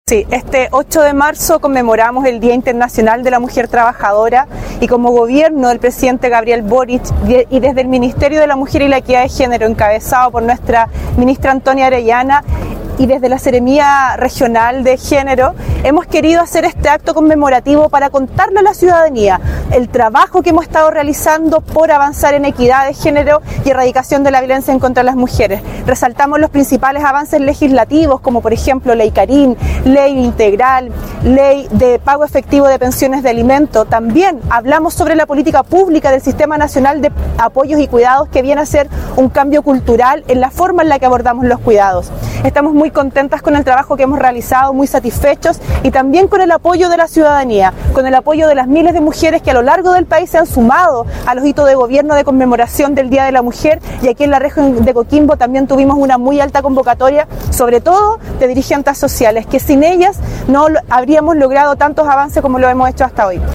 Ante más de 200 personas, entre ellas dirigentas, vecinas, artistas, académicas y autoridades, se conmemoró en el Faro Monumental de La Serena el acto oficial por el Día Internacional de la Mujer.
ACTO-8M-Camila-Sabando-Seremi-Mujer-y-Equidad-de-Genero.mp3